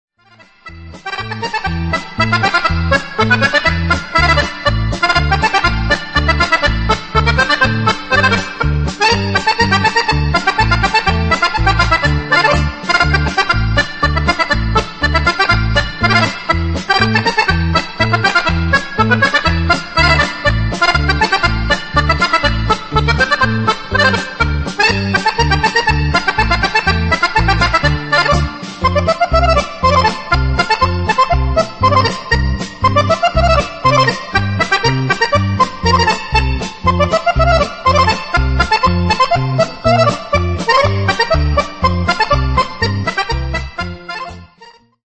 polca